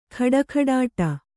♪ khaḍakhaḍāṭa